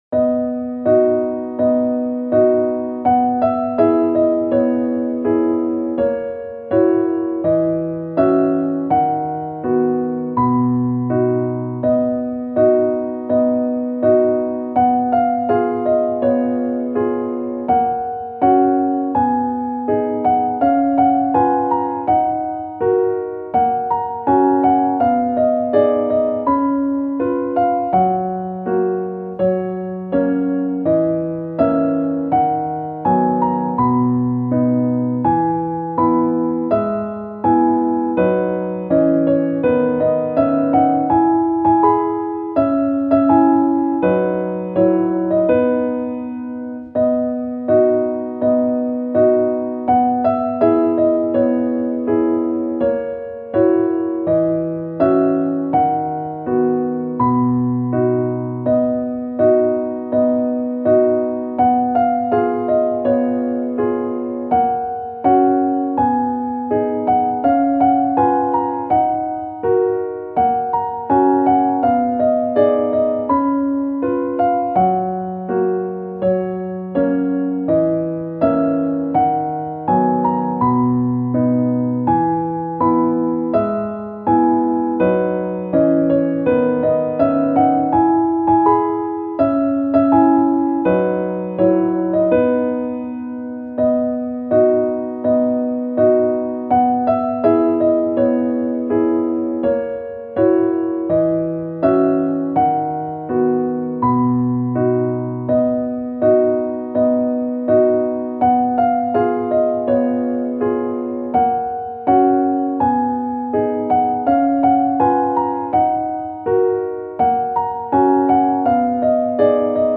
♪サウンドプログラマ制作の高品質クラシックピアノ。
(しっとりピアノ版)